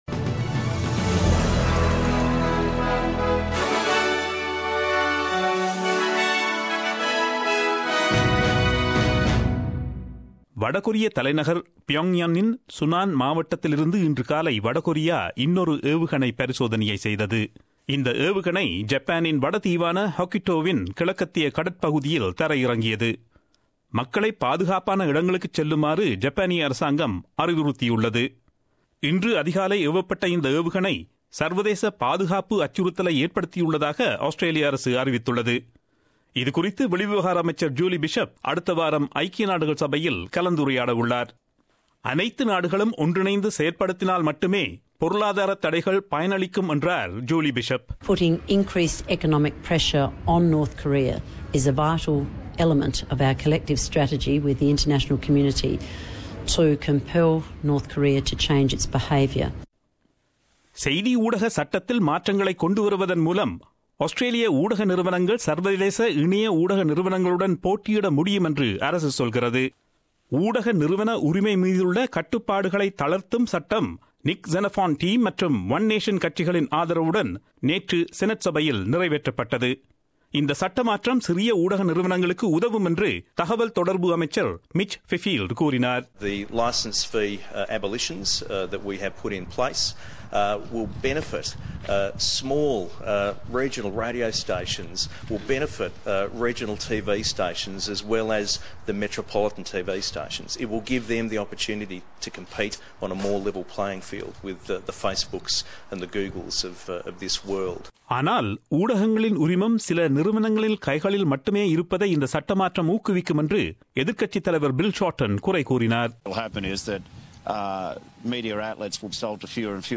Australian news bulletin aired on Friday 15 September 2017 at 8pm.